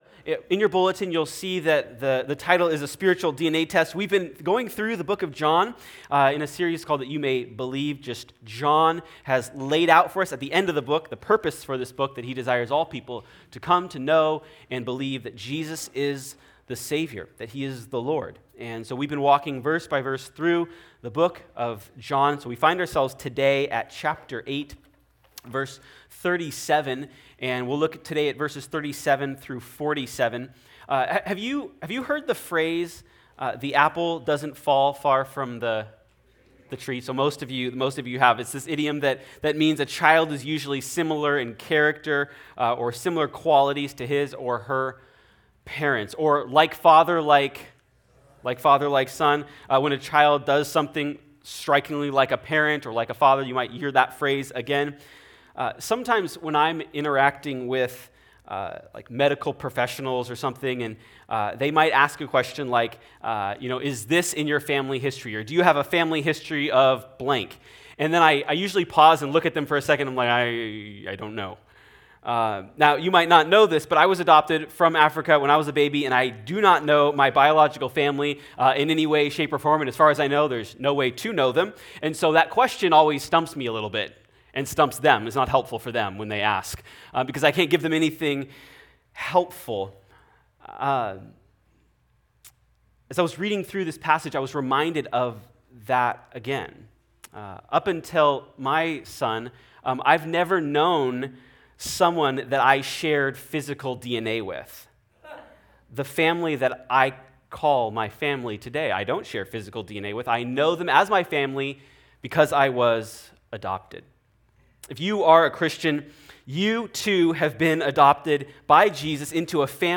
Sermon Notes:Coming soon.